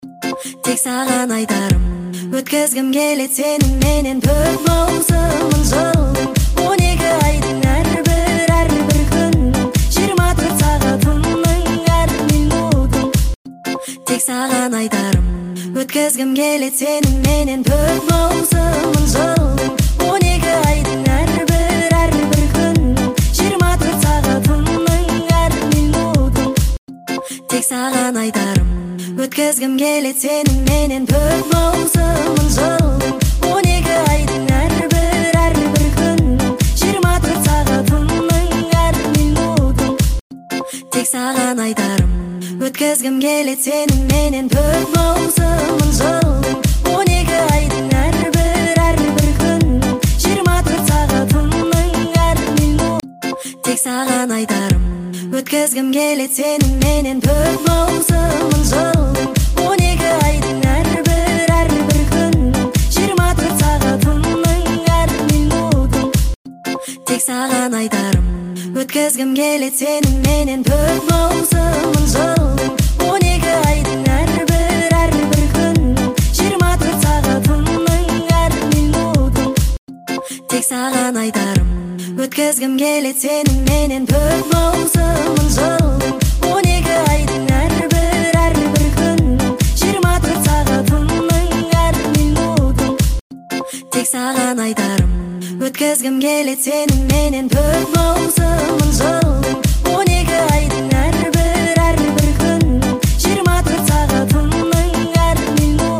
speed up Tiktok remix version